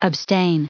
added pronounciation and merriam webster audio